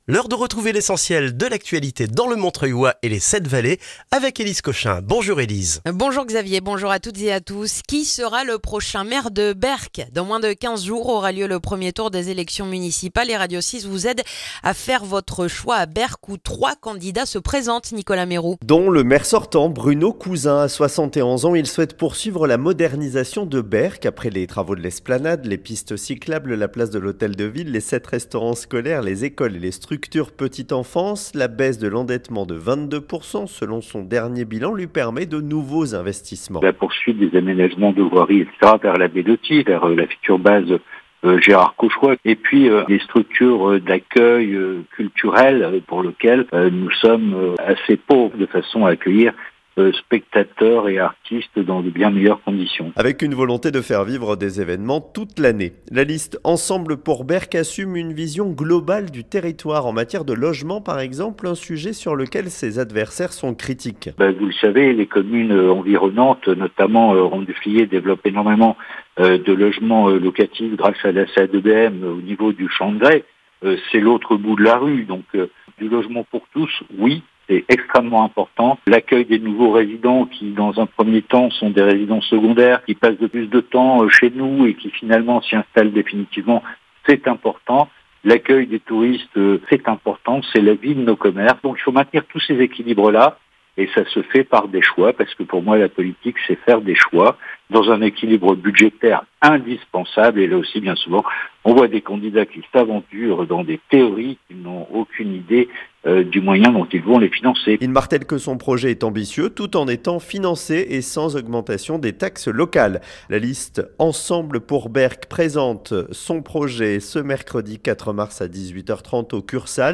Le journal du lundi 2 mars dans le montreuillois